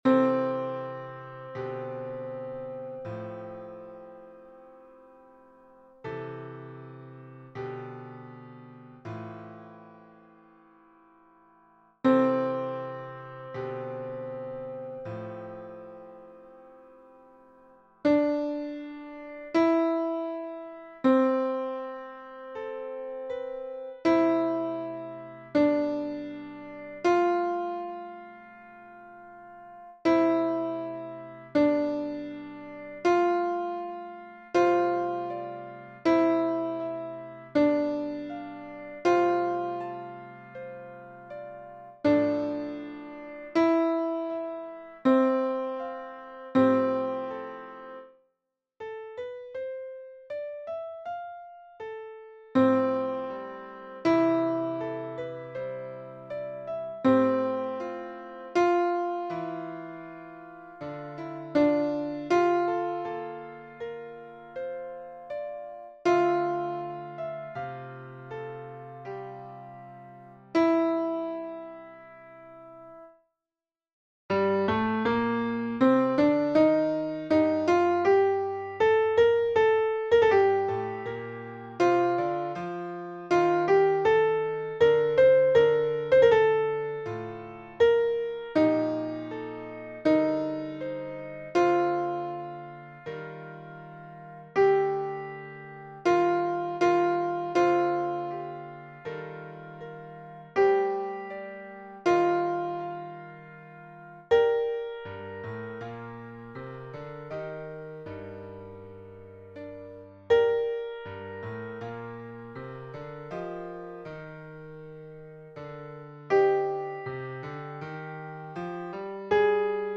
Alto 1 (version piano)